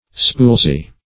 Search Result for " spuilzie" : The Collaborative International Dictionary of English v.0.48: Spuilzie \Spuil"zie\ (sp[u^]l"z[i^] or -y[i^]), n. See Spulzie .